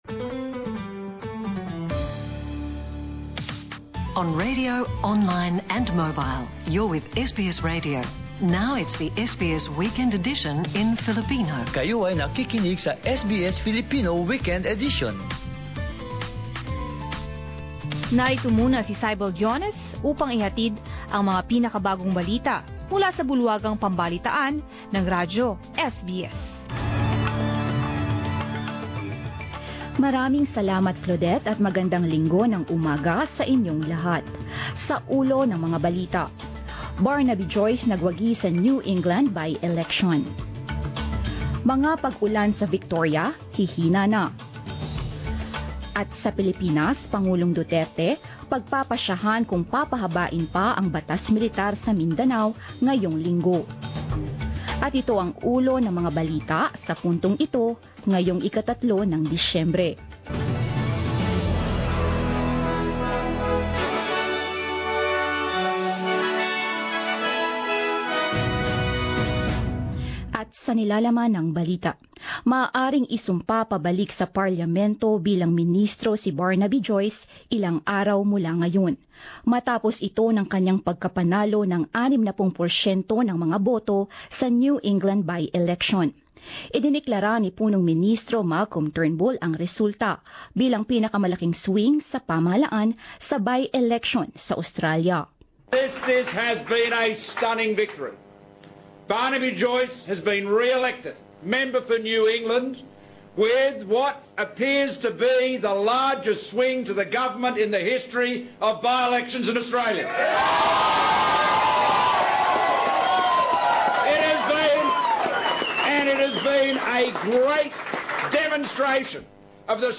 Balita ng alas 10 ng umaga sa Wikang Filipino
10 am Balita sa Wikang Filipino 03 Disyembre 2017, Linggo